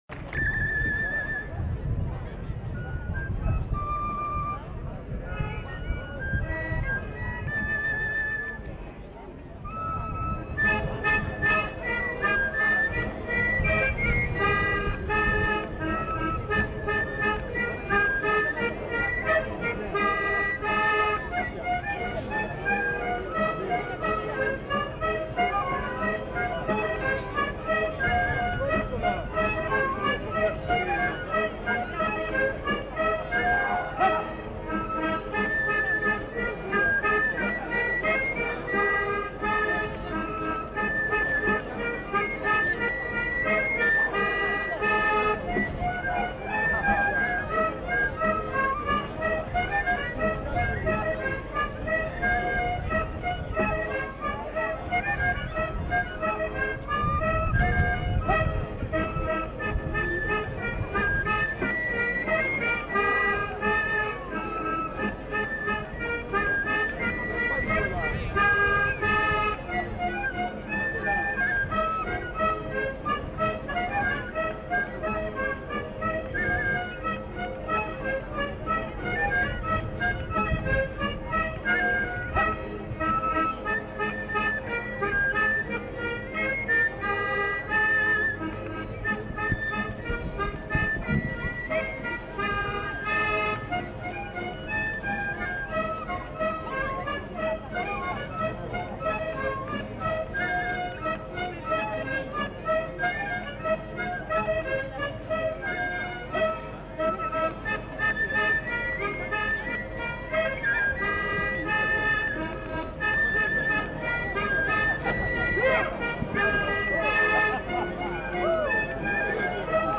Aire culturelle : Béarn
Lieu : Laruns
Genre : morceau instrumental
Instrument de musique : flûte à trois trous ; tambourin à cordes ; accordéon diatonique
Danse : branlo airejan